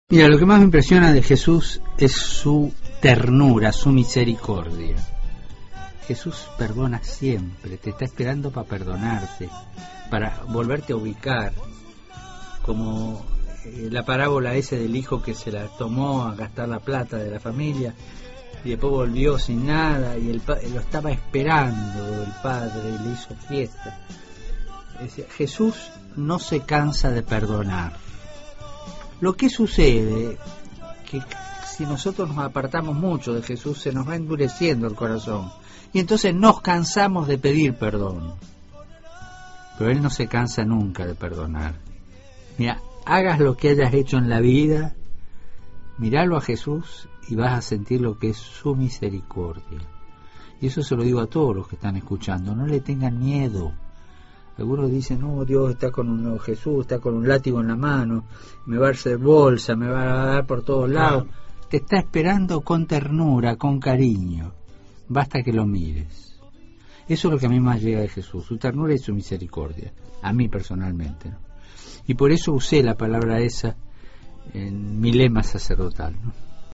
Fragmento de una entrevista que el Papa Francisco concedió hace unos meses a la radio La 96, Voz de Caacupé, emisora de la parroquia Caacupé, en una aldea de Buenos Aires.